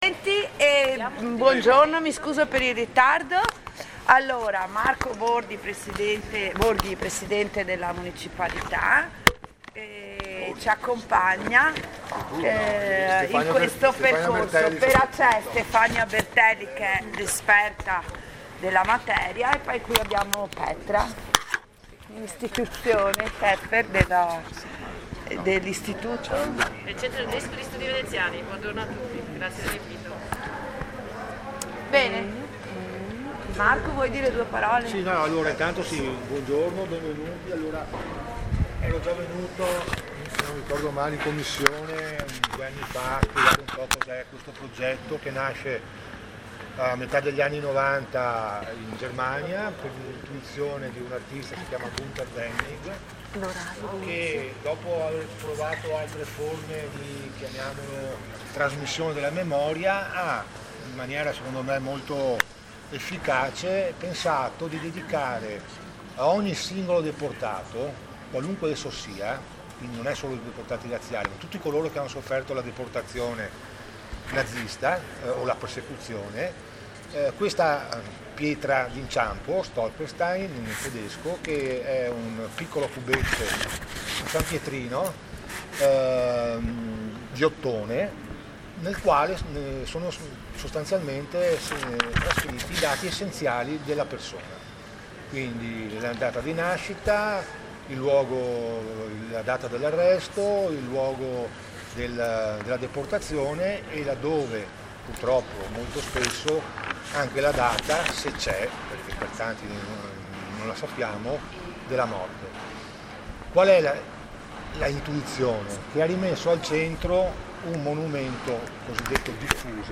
Convocazione e atti della seduta congiunta alla X Commissione
Alle ore 9:56 la presidente Pea,verificato il numero legale apre i lavori della commissione